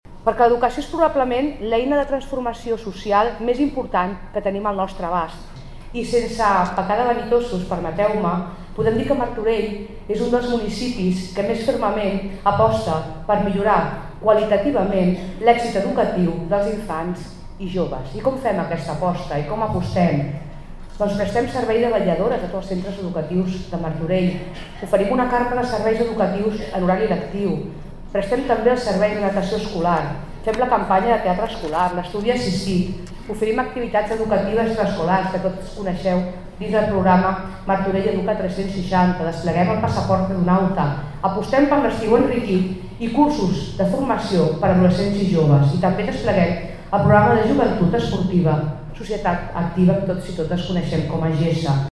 Acte inaugural del curs escolar 2025-26 a Martorell, aquesta tarda al Molí Fariner.
Soledad Rosende, regidora d'Ensenyament